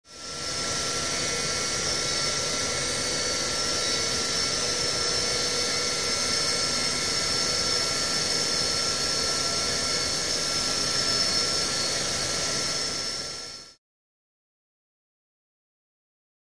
Loud Machine Hum With High Pitched Whines